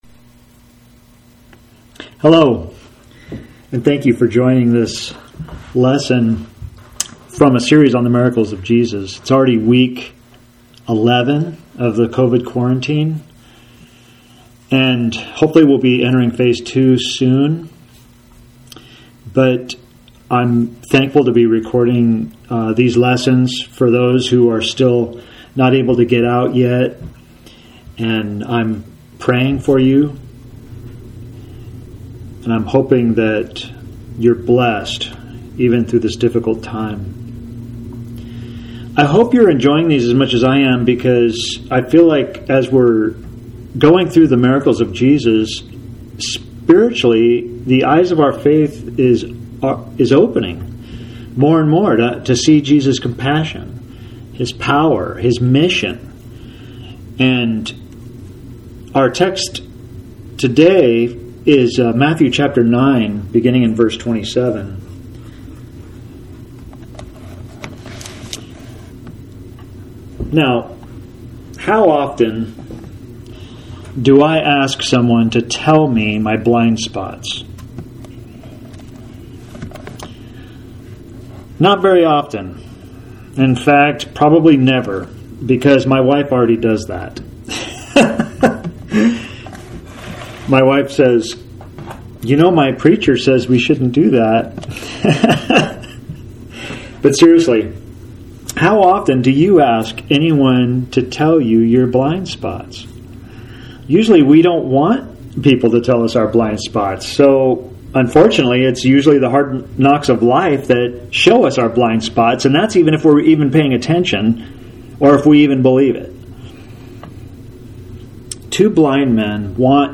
Sermon for Sunday, June 7, 2020.